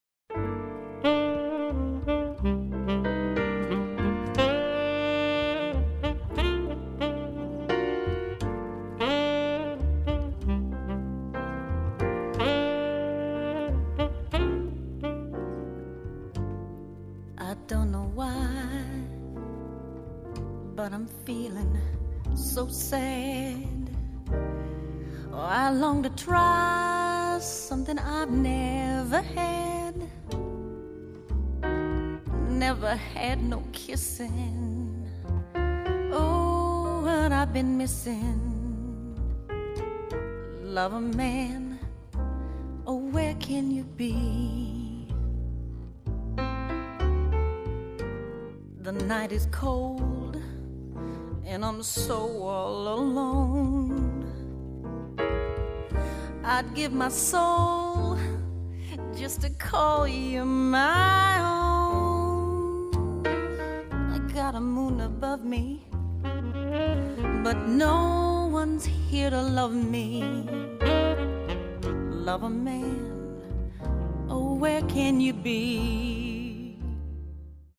A night of jazz to remember.